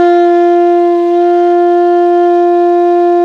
ALTO  PP F 3.wav